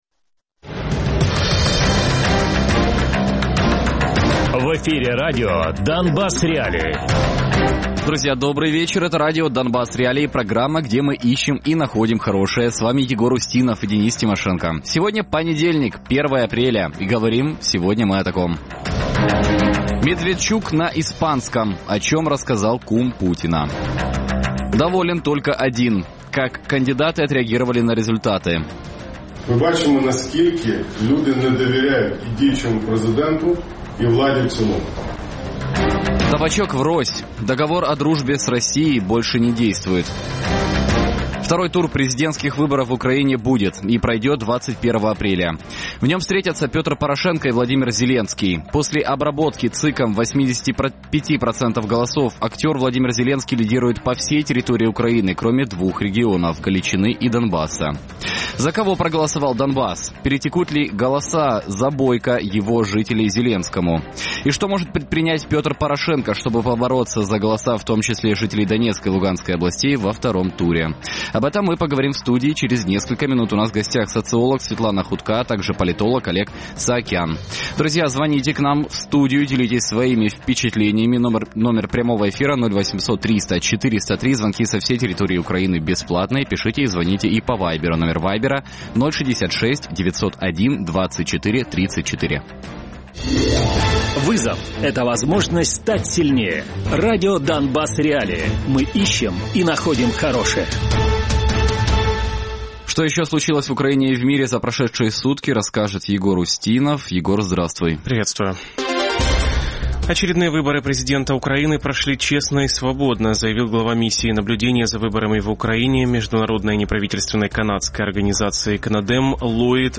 Радіопрограма «Донбас.Реалії» - у будні з 17:00 до 18:00.